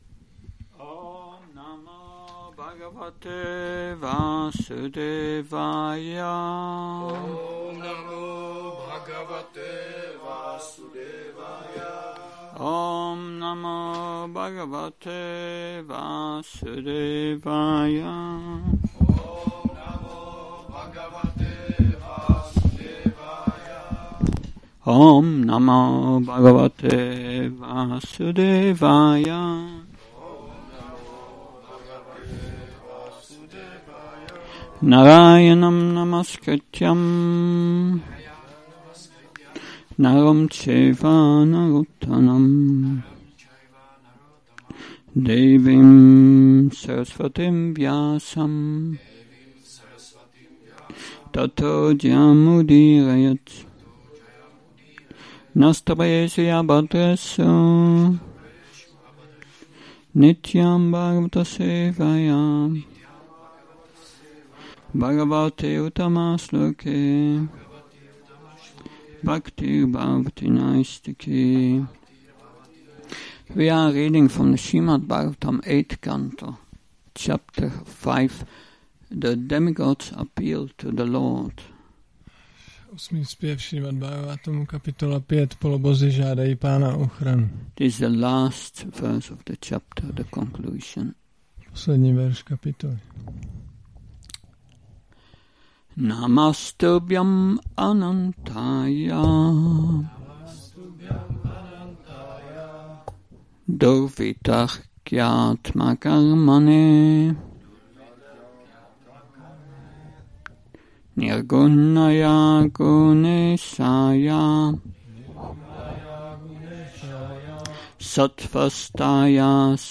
Šrí Šrí Nitái Navadvípačandra mandir
Přednáška SB-8.5.49